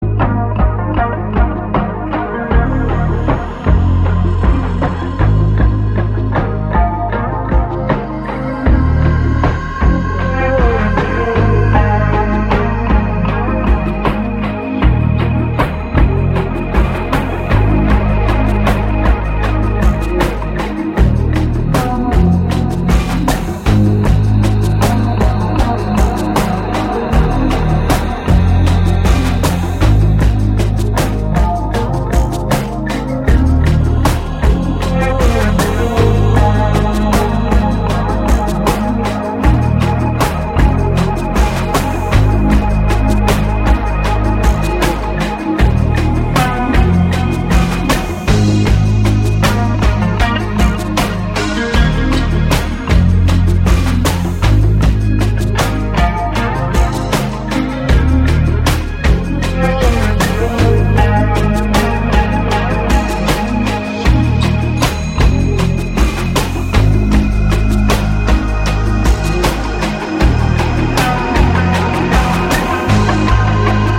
インディ感濃厚なシューゲイズ・アンビエントを披露。